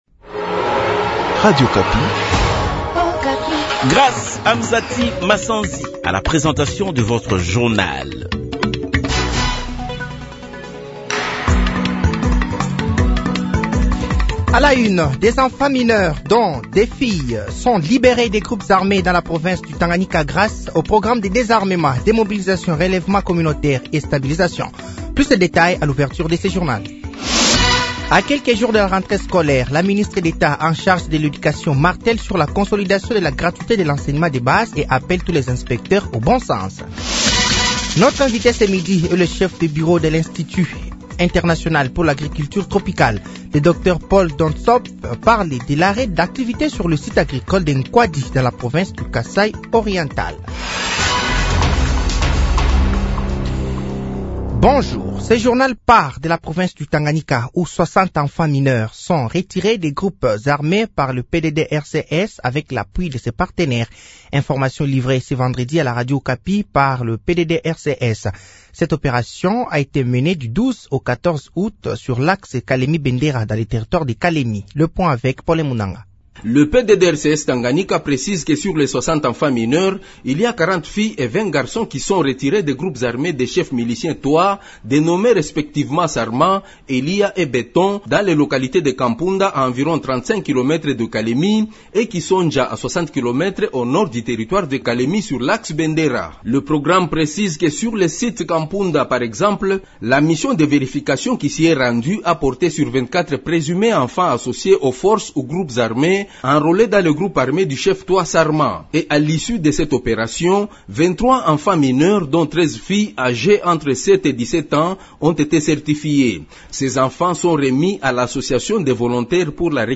Journal midi
Journal français de 12h de ce dimanche 18 août 2024